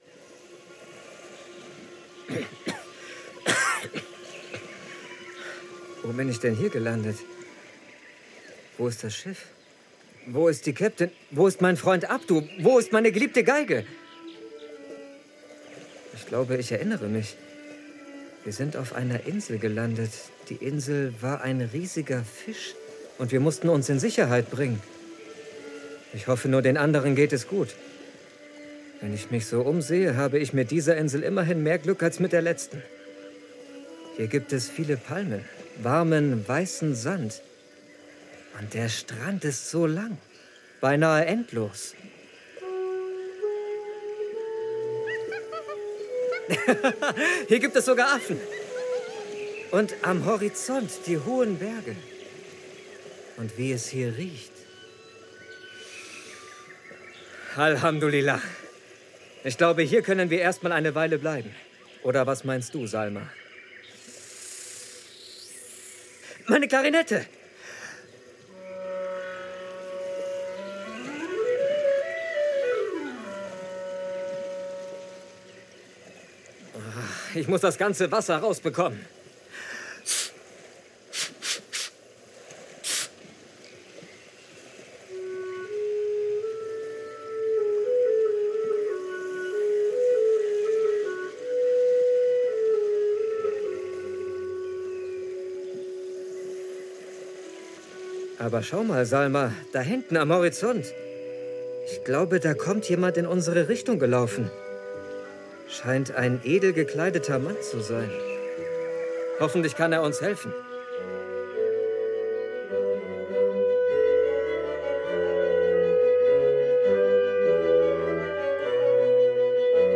Stereo,